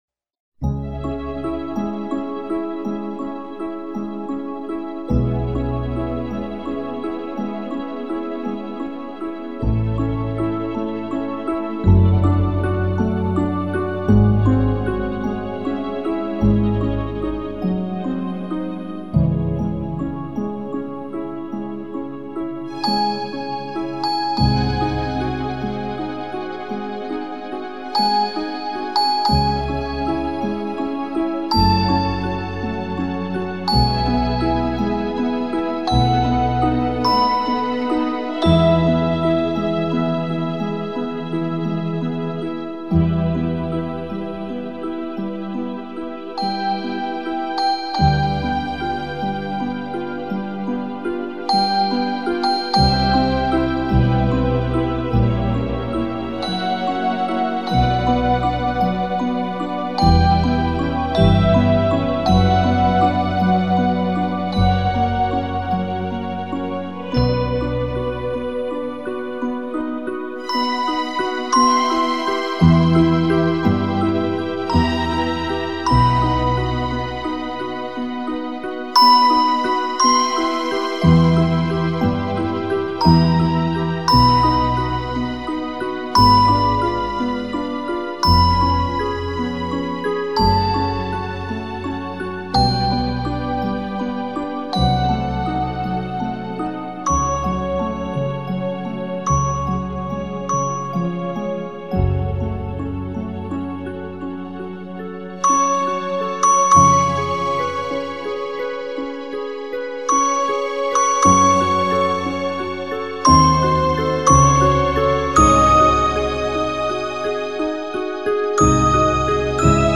Лунная соната - нежная колыбельная, классика - слушать онлайн
Нежная и расслабляющая мелодия классического произведения для детей.